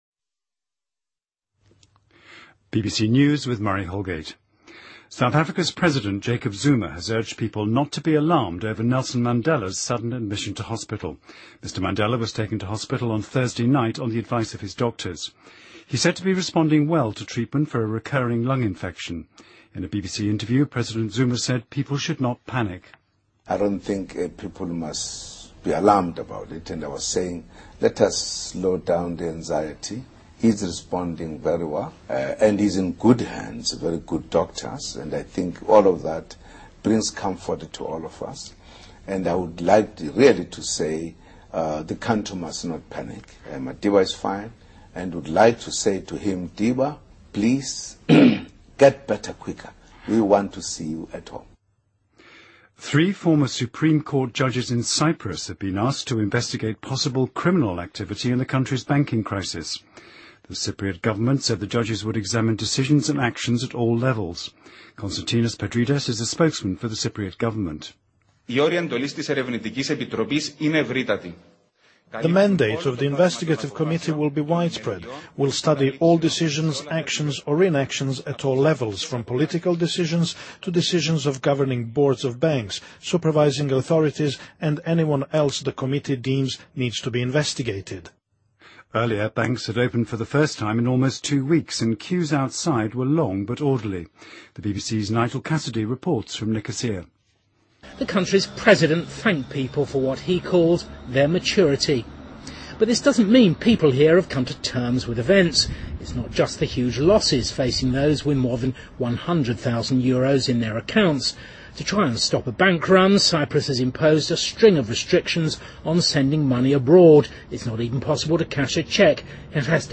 BBC news,2013-03-29